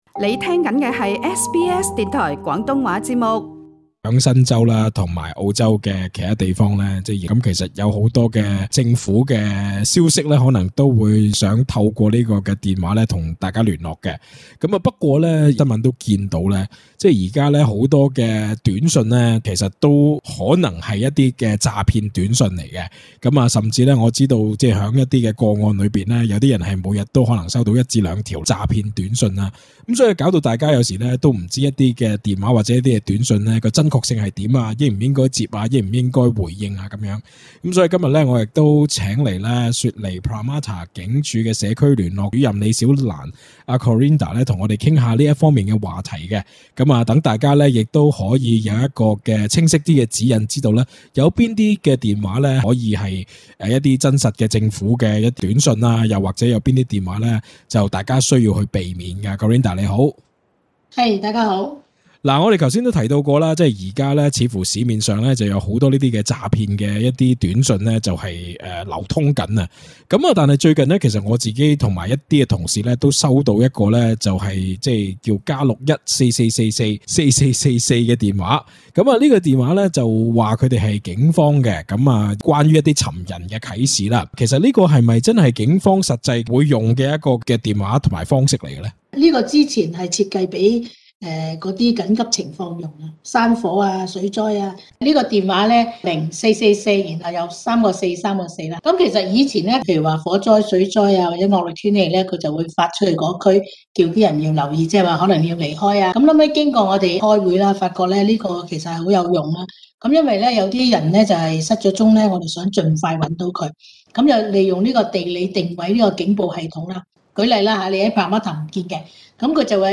手機詐騙訊息猖獗，但同時警方及新冠追踪組亦會用短訊的方式與市民聯絡，SBS廣東話節目組請來警方代表，教大家如何分辦警方或當局發出的短訊。